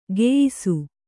♪ geyisu